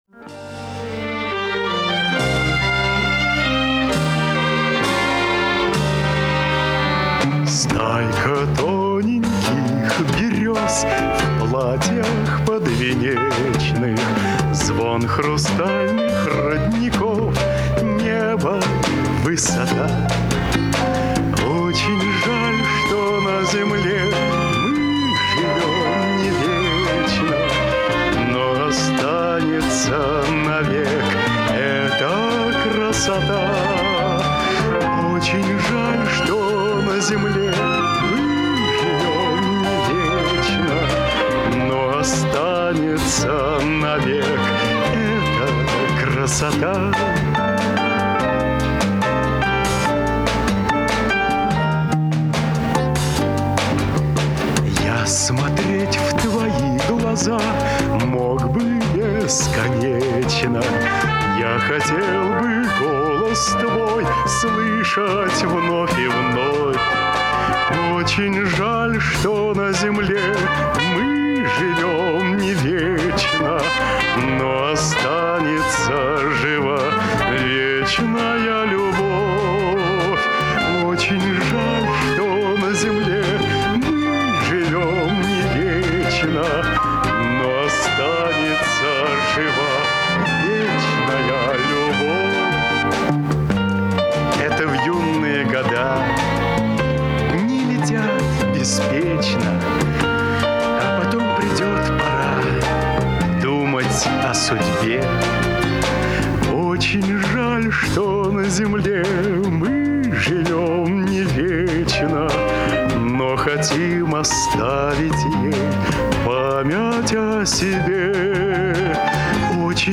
небольшой баритон приятного сочного тембра.
поёт без кантилены, диапазон голоса небольшой.